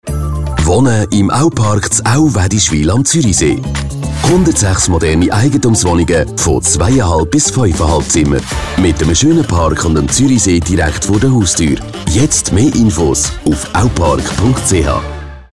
Radiospot (Aupark) – 15 Sekunden